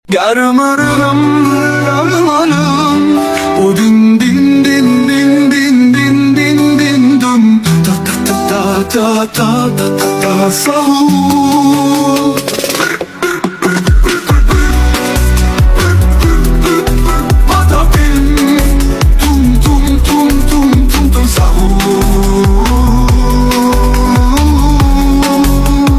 brainrot ladies |middle eastern stlye